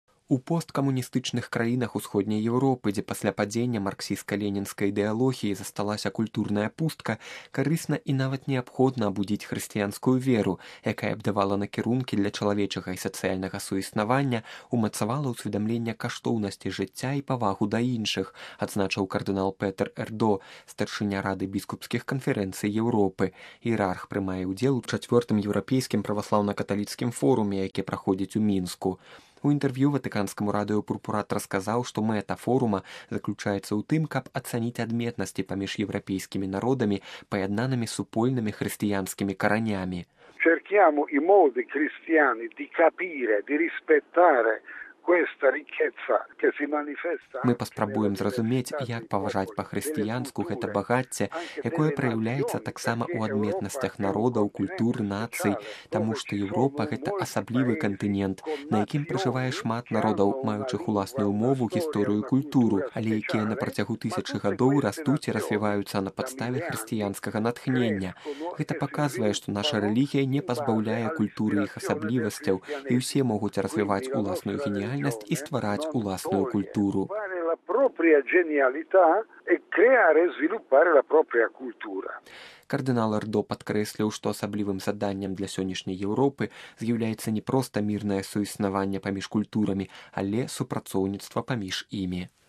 У інтэрв’ю Ватыканскаму радыё пурпурат расказаў, што мэта форума заключаецца ў тым, каб ацаніць адметнасці паміж еўрапейскімі народамі, паяднанымі супольнымі хрысціянскімі каранямі.